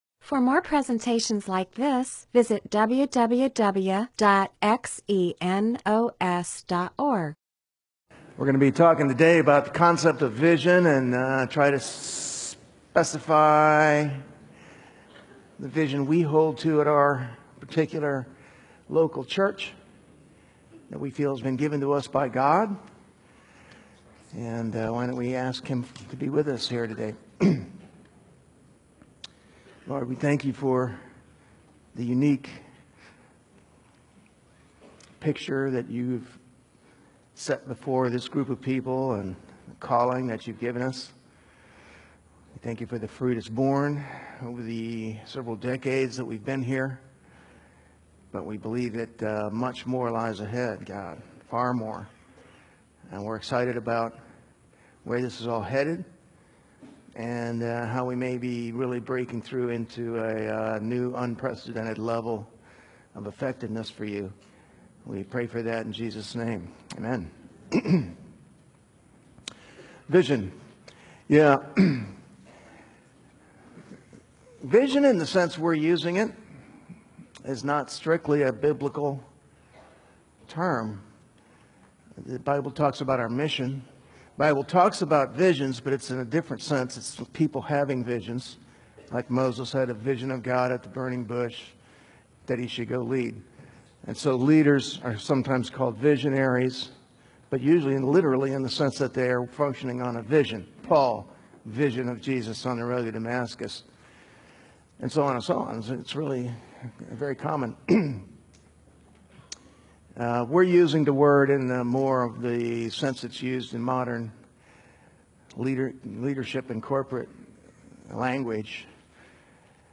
MP4/M4A audio recording of a Bible teaching/sermon/presentation about .